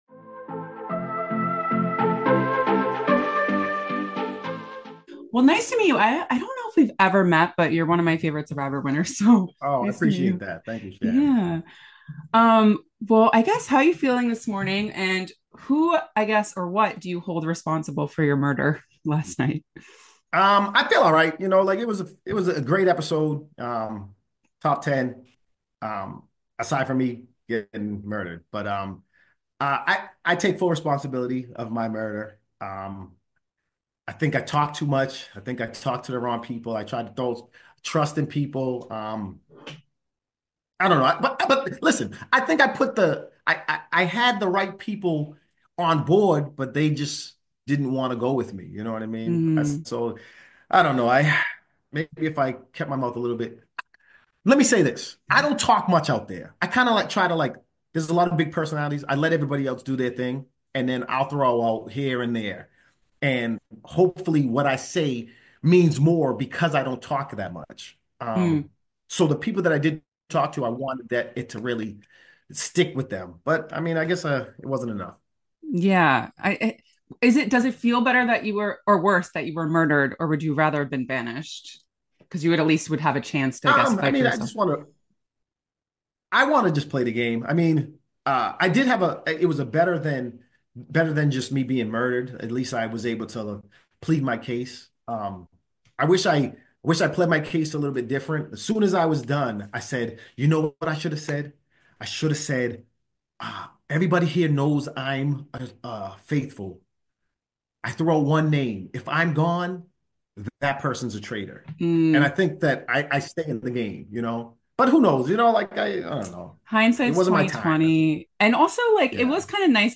The Traitors Exit Interview: Jeremy Collins Reacts to Being Murdered by Fellow Survivors